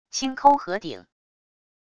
轻抠盒顶wav音频